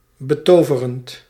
Ääntäminen
Synonyymit beau Ääntäminen France: IPA: [sy.pɛʁb] Haettu sana löytyi näillä lähdekielillä: ranska Käännös Ääninäyte Adjektiivit 1. betoverend 2. beeldig 3. verrukkelijk 4. heerlijk Suku: f .